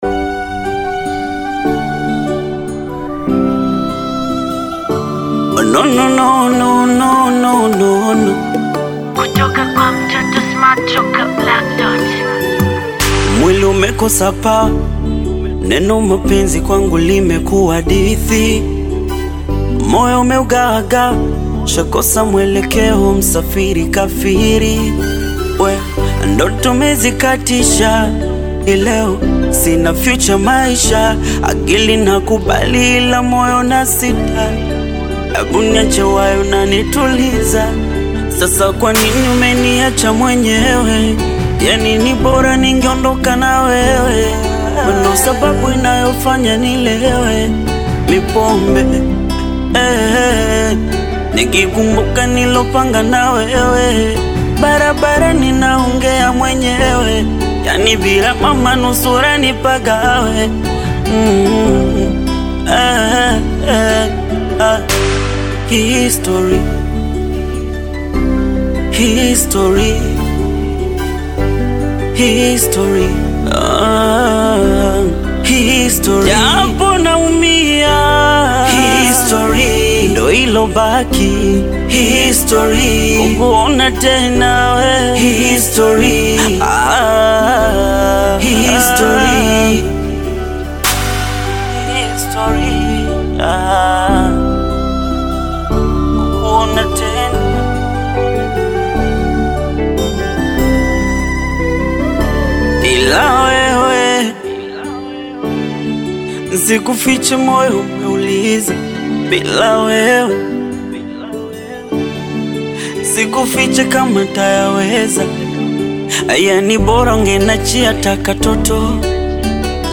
Bongo Flava music track
Tanzanian Bongo Flava artist, singer, and songwriter
Bongo Flava